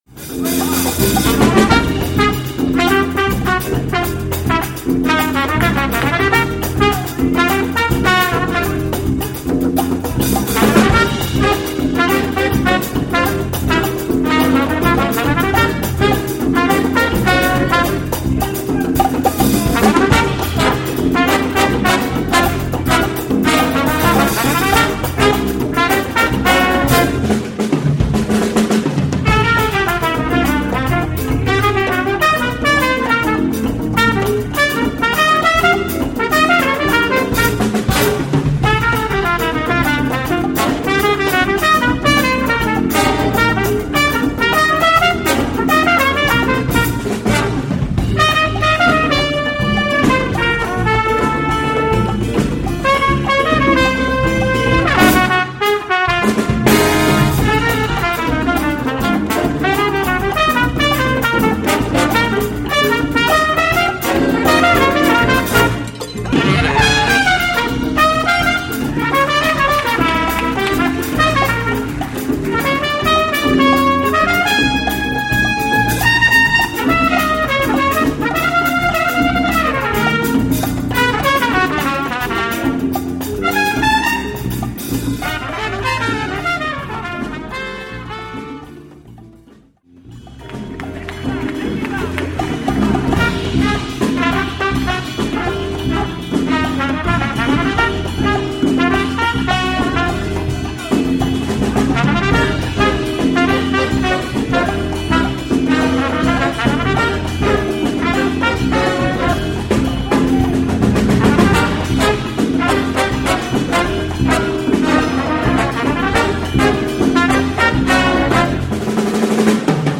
Category: big band
Style: mambo/salsa
Solos: open
Instrumentation: big band (salsa, rhythm (4)